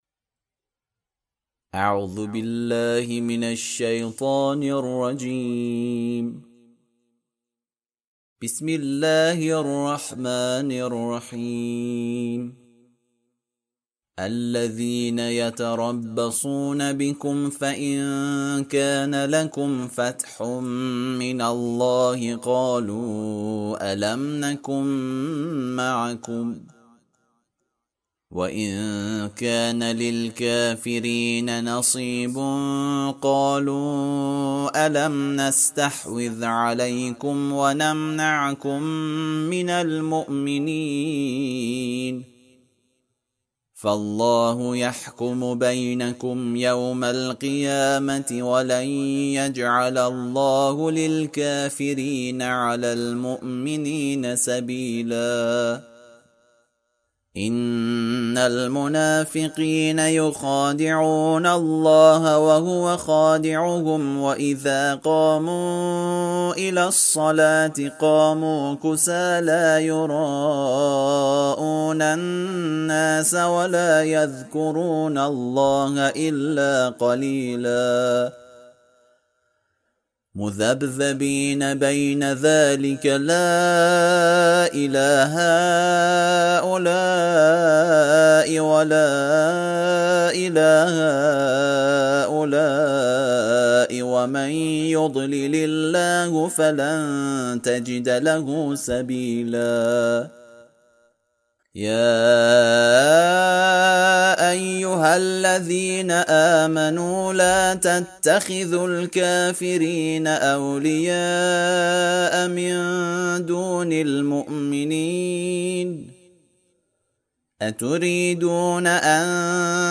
ترتیل صفحه ۱۰۱ سوره مبارکه نساء(جزء پنجم)
ترتیل سوره(نساء)